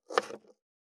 521厨房,台所,野菜切る,咀嚼音,ナイフ,調理音,まな板の上,料理,
効果音